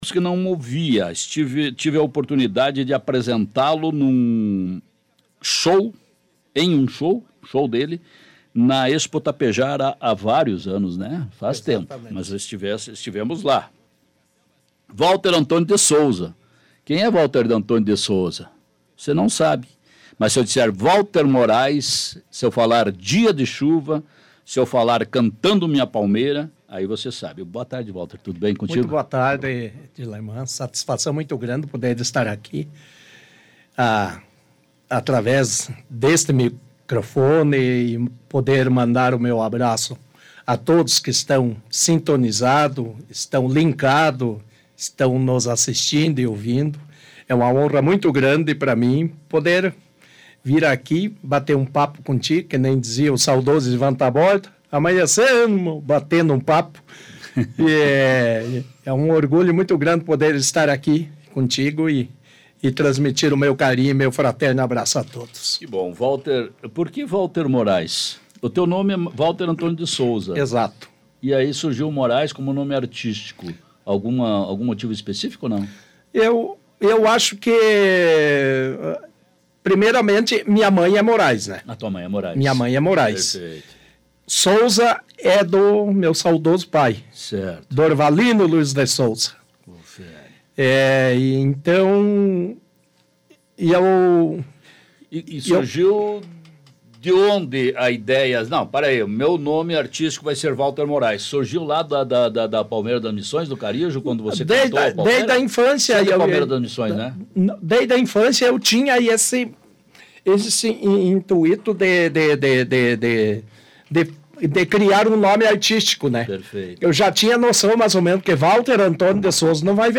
O programa A Primeira Hora vai ao ar de segunda a sexta-feira, das 13h às 14h, ao vivo na Planalto News FM 92.1.
ao vivo na Planalto News FM 92.1. Acompanhe a entrevista: https